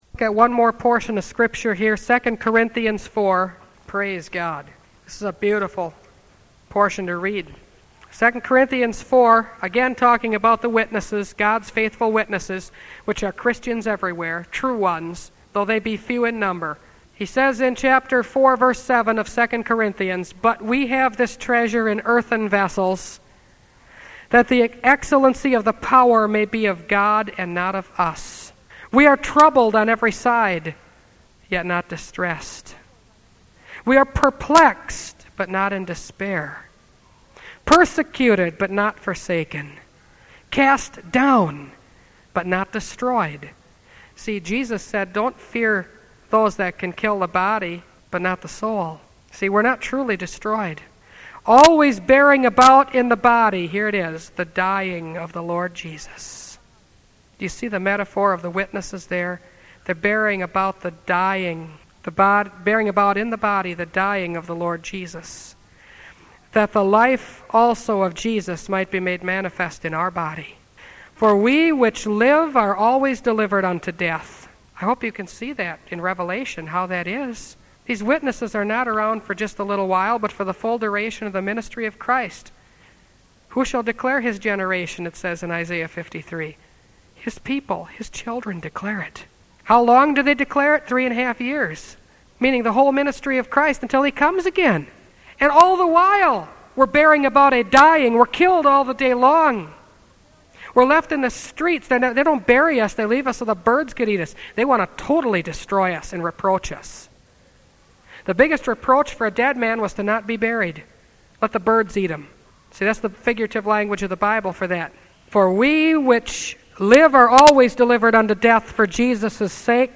Revelation Series – Part 18 – Last Trumpet Ministries – Truth Tabernacle – Sermon Library